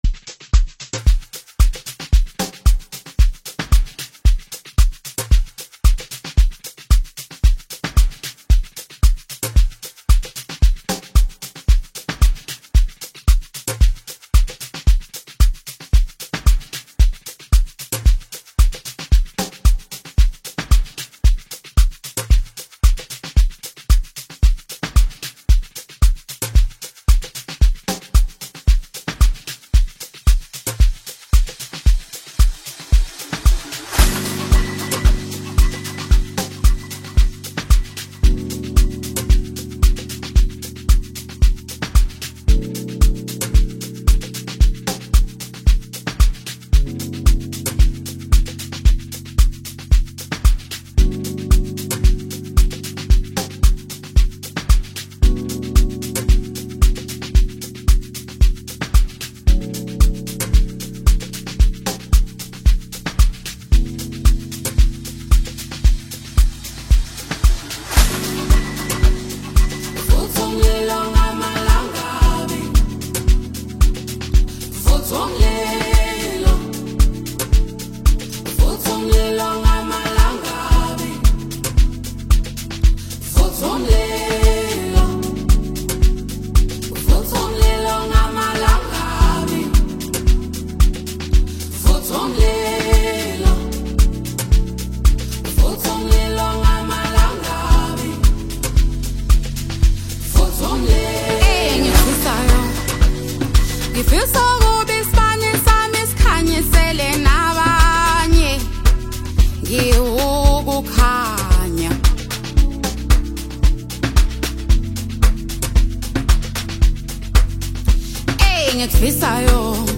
Amapiano Songs